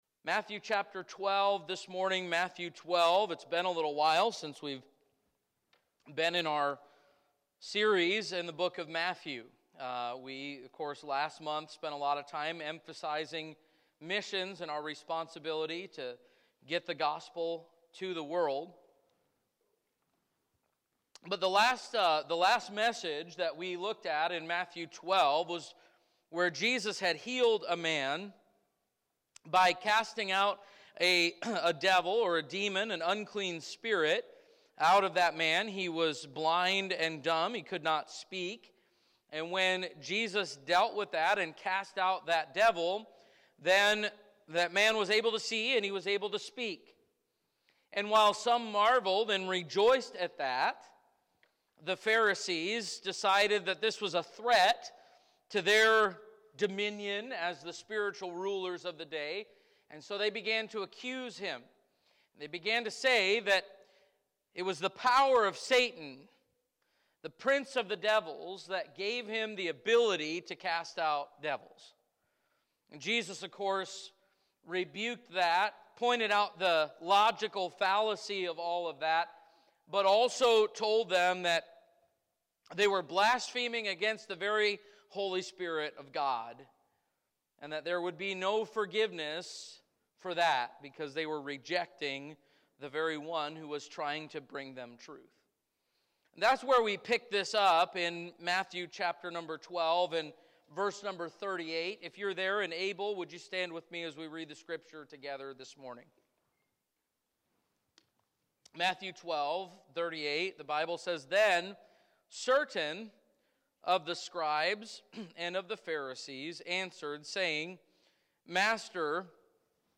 Matthew 12:38-45 Sunday Morning